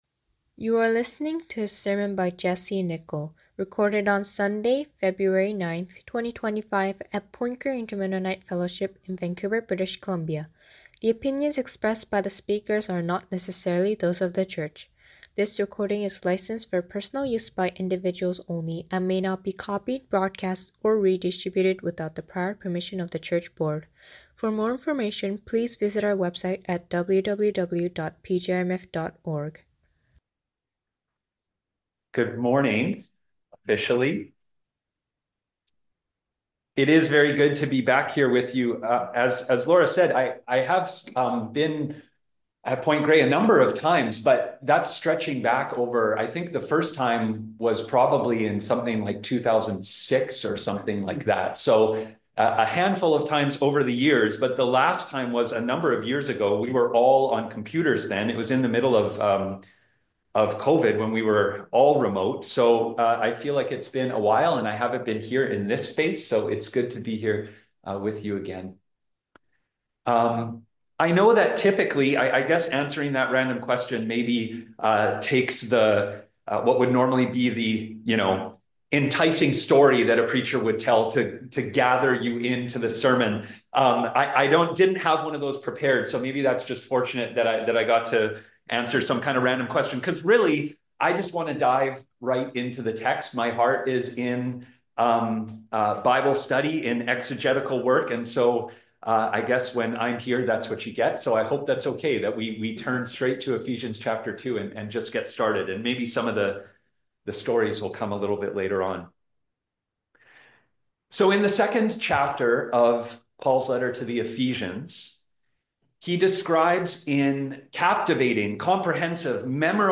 Sunday Services For 2025 - Point Grey Inter-Mennonite Fellowship